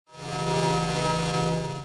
secret_discovered.ogg